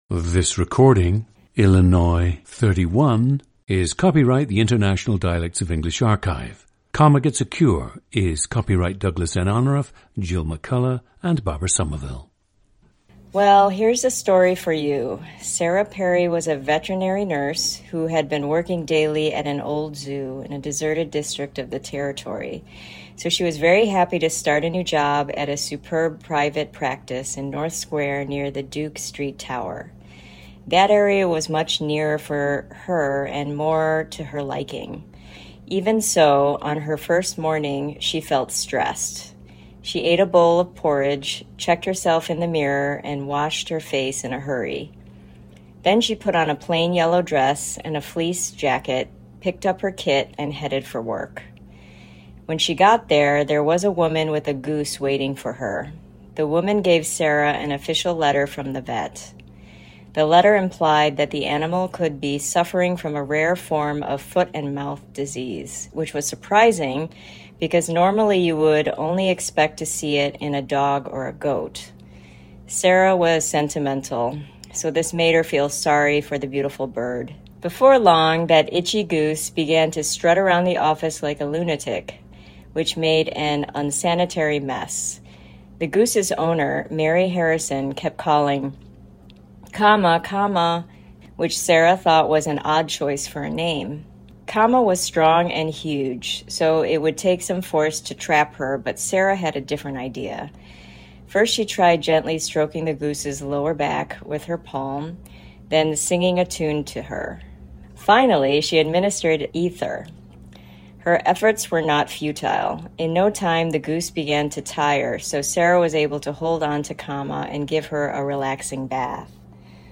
GENDER: female
She grew up with a father who speaks Japanese.
The subject’s accent and oral posture reflect some Midwestern pronunciations/resonance characteristics; note slight lip spread and raised tongue, heard specifically on LOT, TRAP, and START words (lot, Comma, back in Japan, can’t imagine, dad, hard) and several R-vowel words (her, rare, Sarah, retired).
• Recordings of accent/dialect speakers from the region you select.
The recordings average four minutes in length and feature both the reading of one of two standard passages, and some unscripted speech.